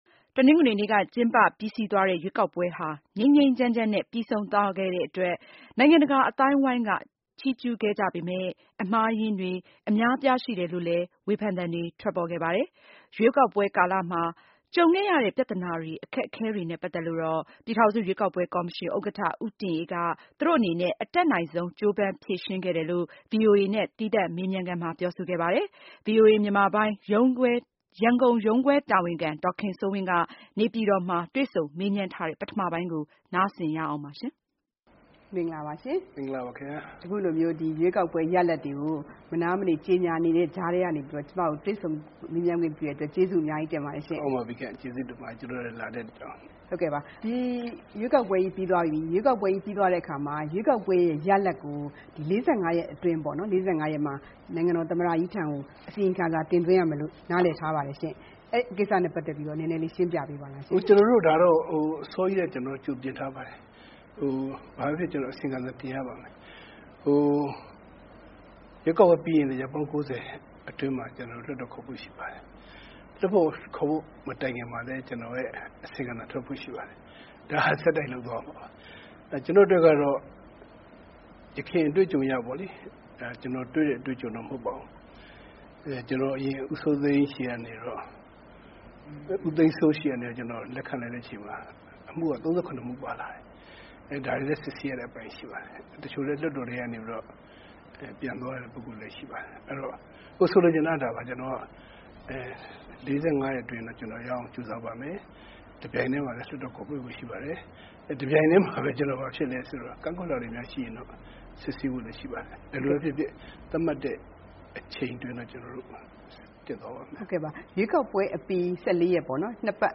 ပြည်ထောင်စုရွေးကောက်ပွဲကော်မရှင်ဥက္ကဋ္ဌ ဦးတင်အေးနဲ့ တွေ့ဆုံမေးမြန်းခန်း